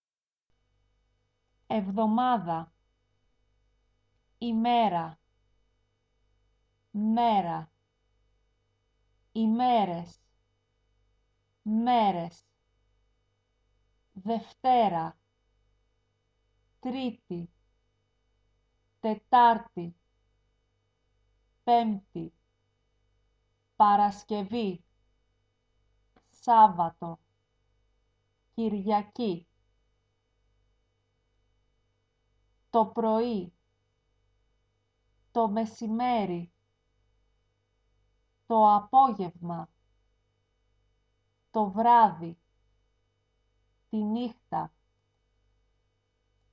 Lyssna hur man uttalar de blåmärkta orden nedan: